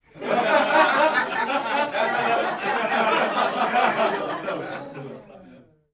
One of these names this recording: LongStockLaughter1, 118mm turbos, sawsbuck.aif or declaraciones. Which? LongStockLaughter1